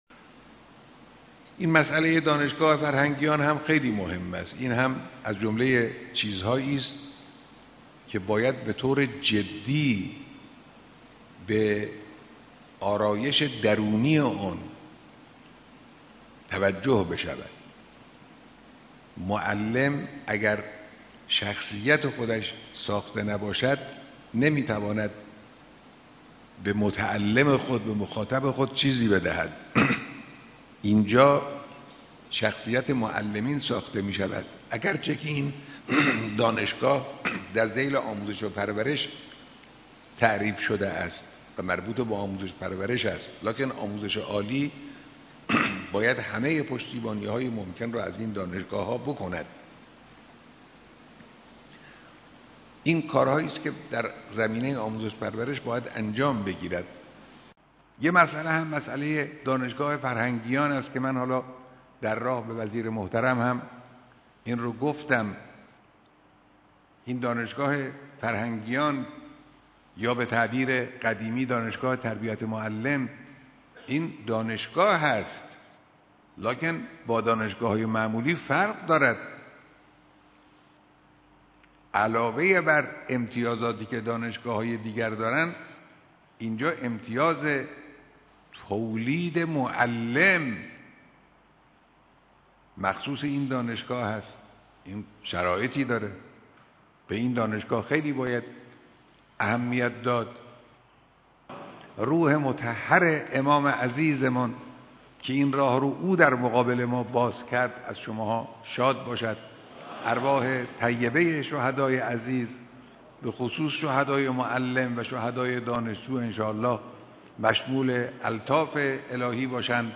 سخنان رهبری